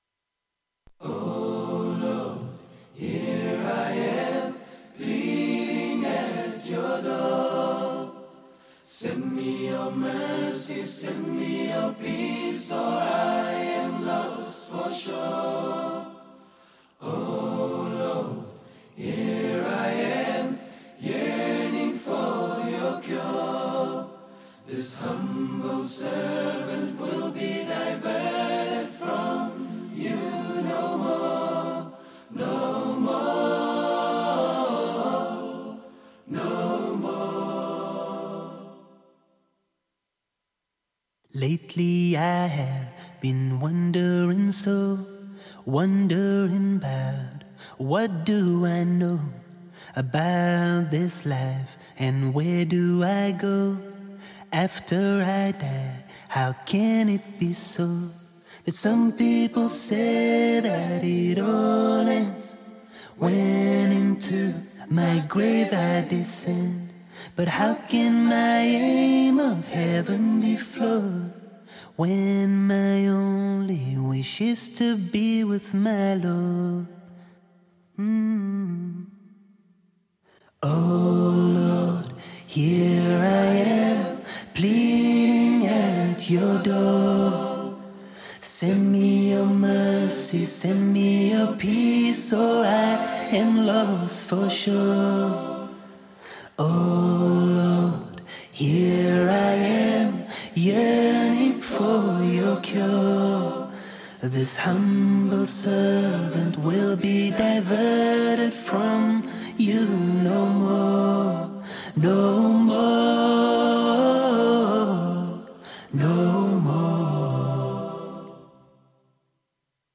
Nashid